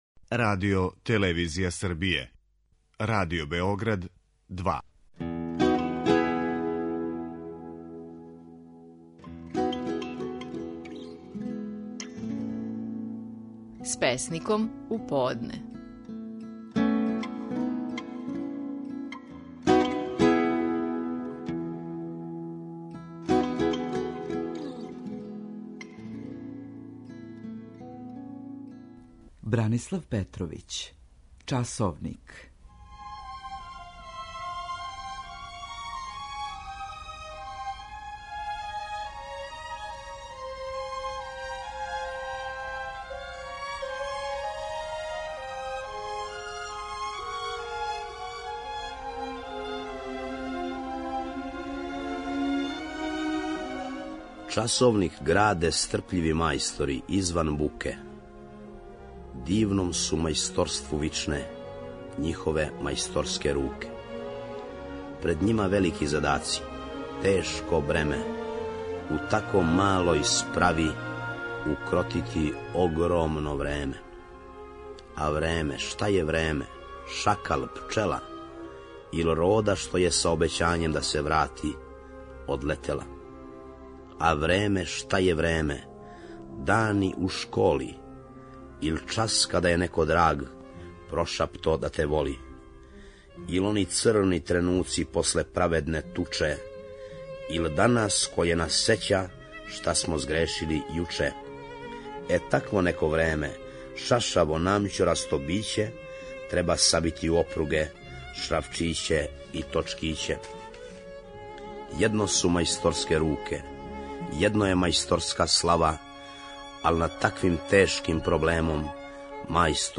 Стихови наших најпознатијих песника, у интерпретацији аутора.
Брана Петровић говори своју песму "Часовник".